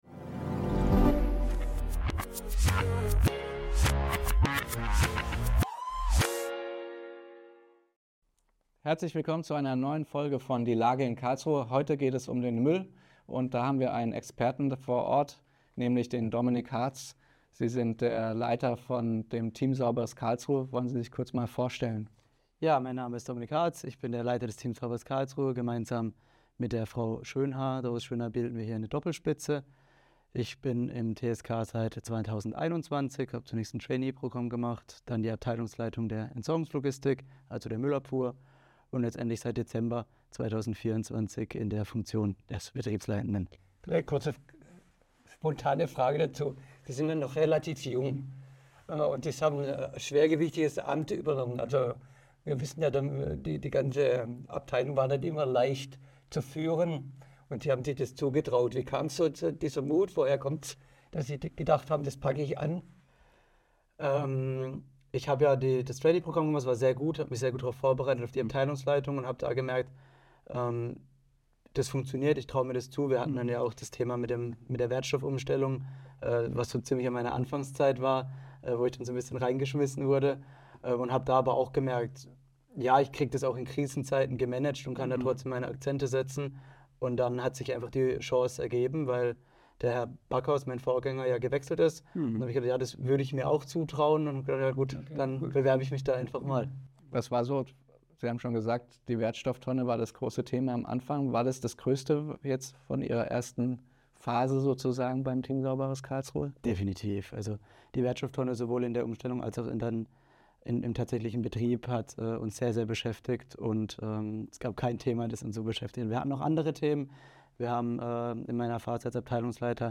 Finanzkrise Karlsruhe: Die fehlenden Millionen mit Gabriele Luczak-Schwarz 30.05.2025 31 min Episode als mp3 herunterladen Krisenzeiten, Streichungen, politische Verantwortung – das sind die Herausforderungen, mit denen Gabriele Luczak-Schwarz, Erste Bürgermeisterin und Finanzbürgermeisterin von Karlsruhe, derzeit konfrontiert ist.